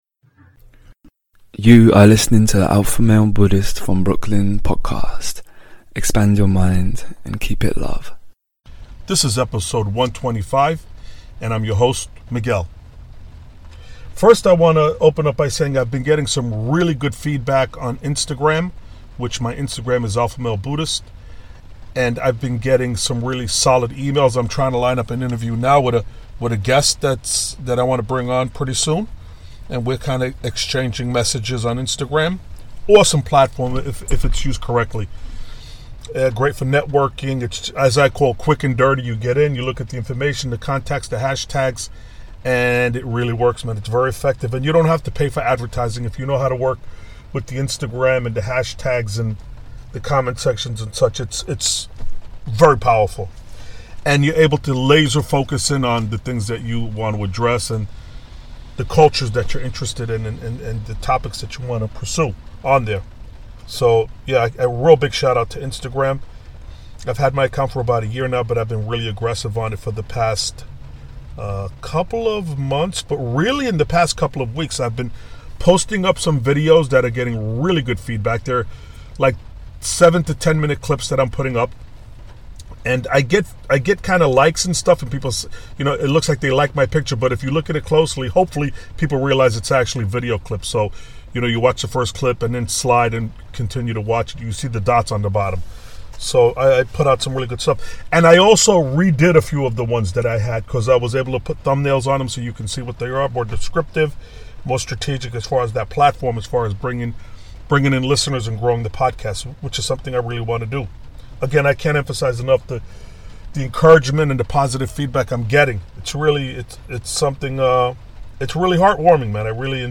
EP 125 - Must Listen - The Teachings Of Don Juan - A Yaqui Way of Knowledge audio Book - Carlos Castaneda